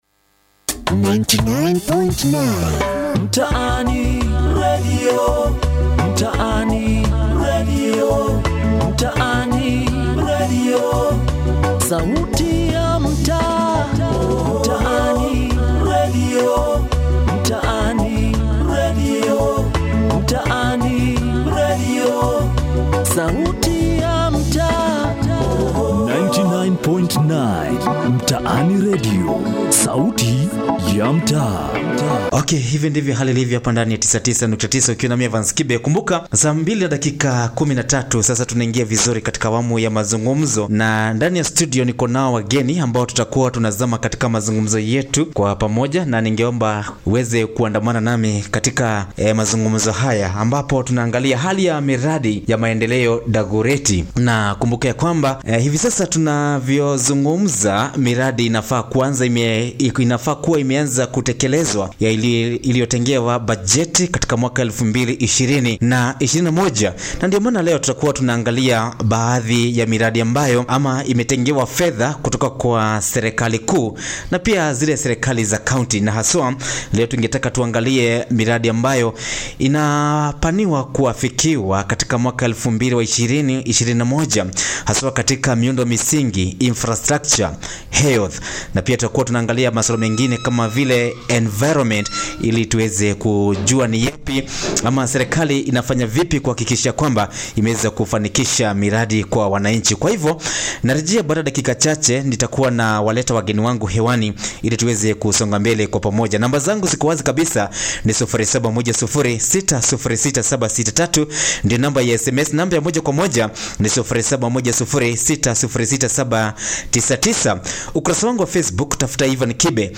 MTAANI RADIO SHOW ON SANITATION
MTAANI RADIO SHOW ON SANITATION.mp3